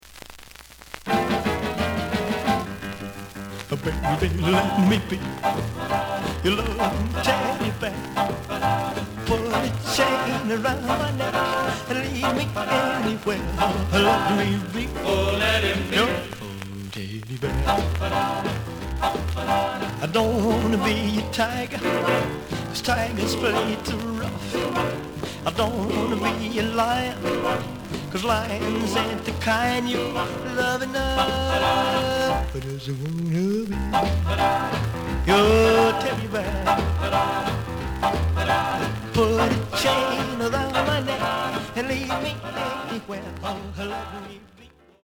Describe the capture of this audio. The audio sample is recorded from the actual item. ●Format: 7 inch Some noise on both sides.